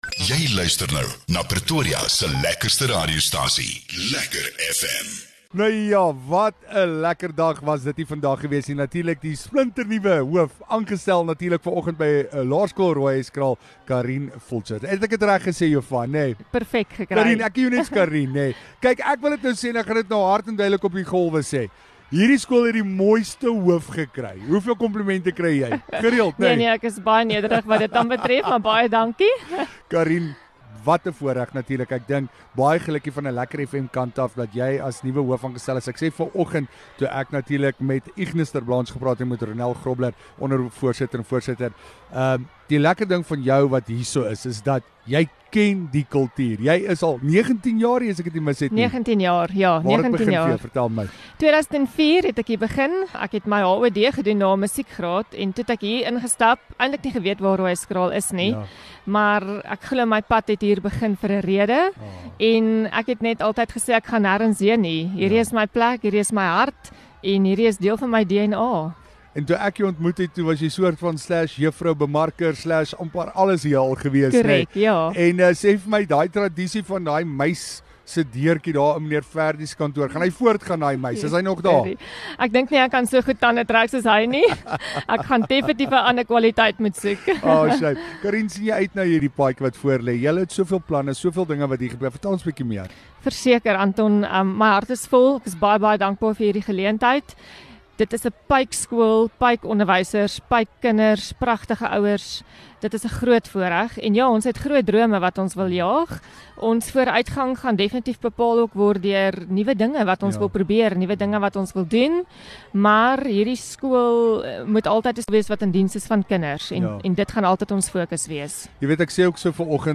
LEKKER FM | Onderhoude 2 Jun Die Laerskool Rooihuiskraal